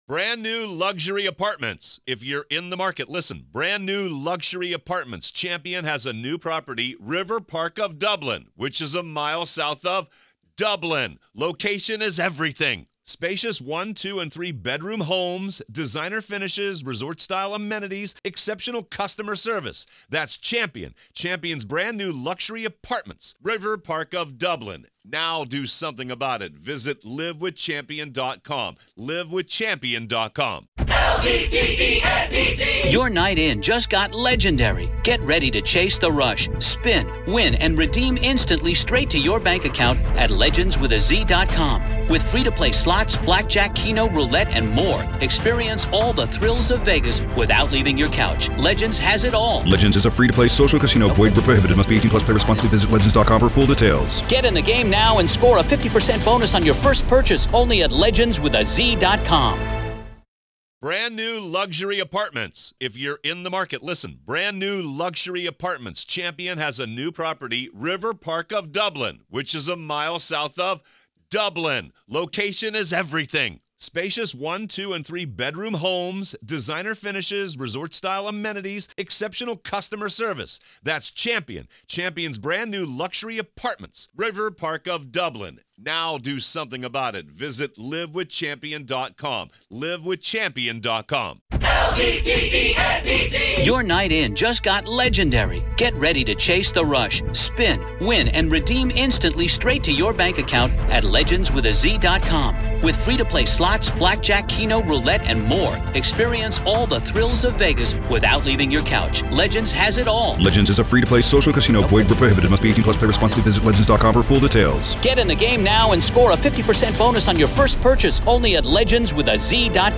In this full-length interview